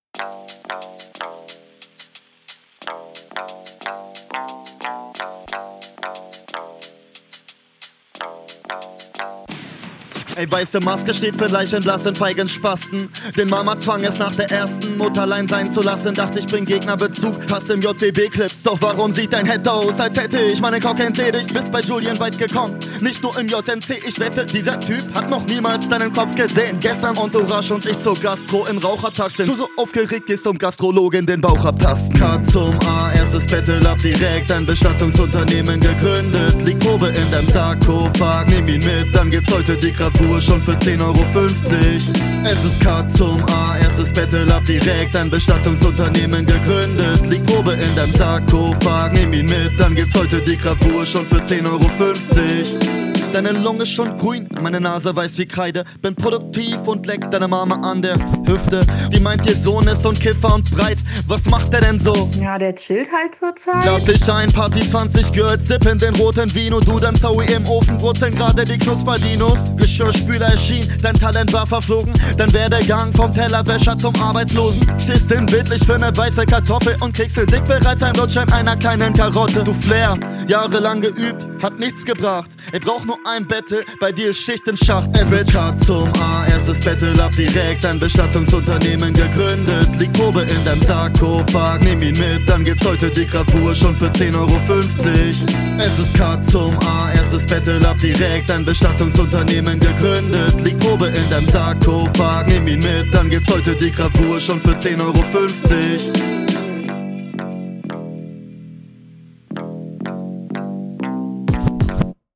Sauber gerappt, flowtechnisch auch sehr routiniert.
Beat ist Nice sagt mir gut zu.